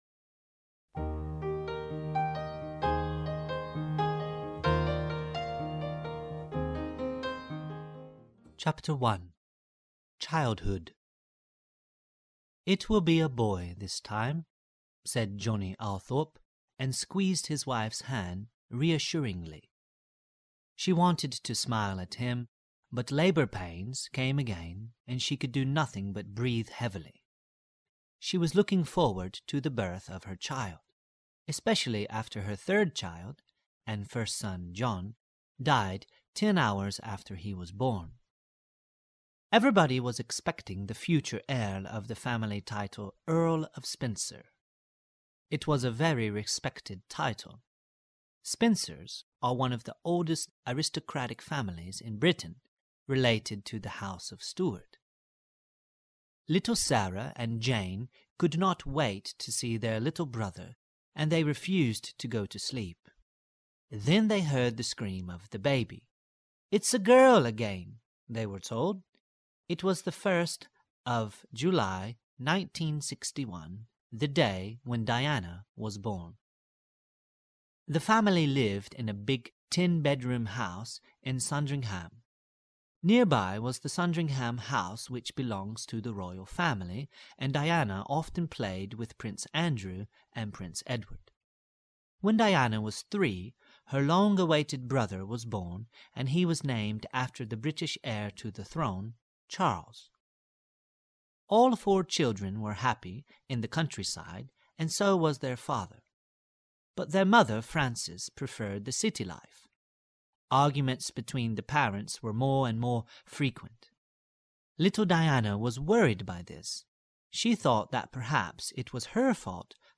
Audiokniha nabízí příběh v angličtině, který zachycuje nejdůležitějších...